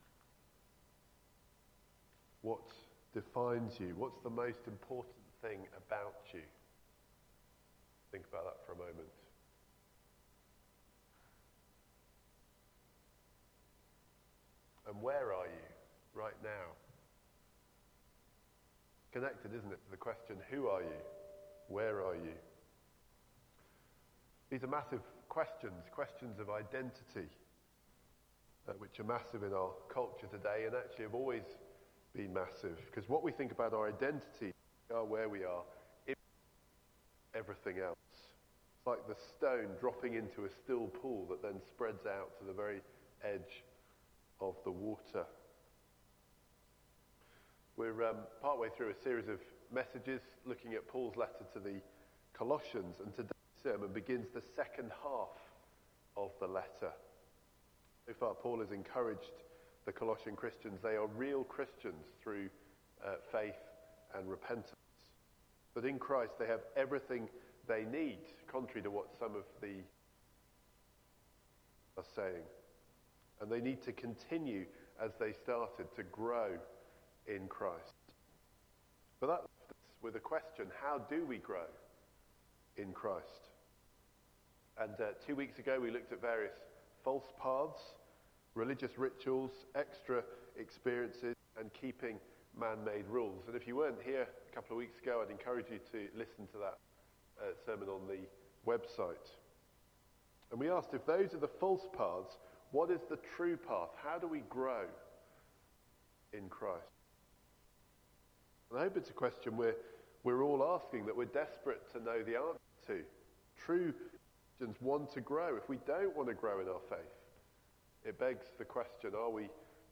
Media Library The Sunday Sermons are generally recorded each week at St Mark's Community Church.
Theme: Knowing we belong in heaven Sermon